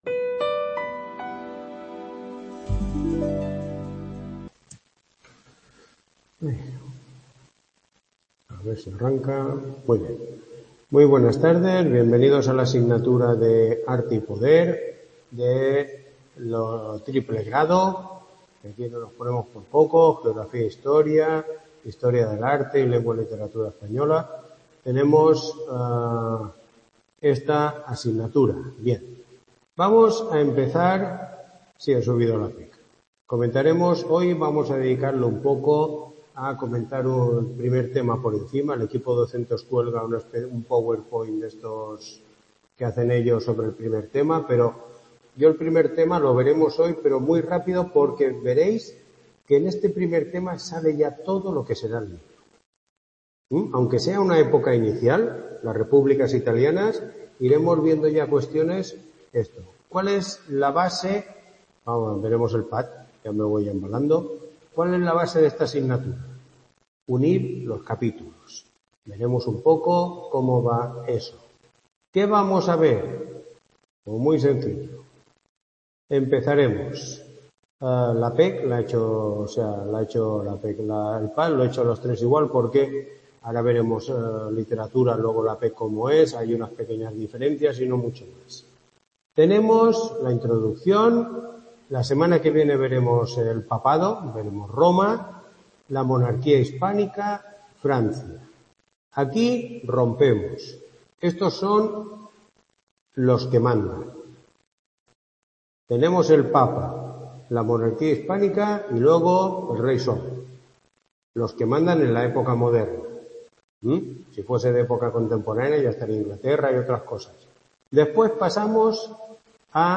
Tutoría 1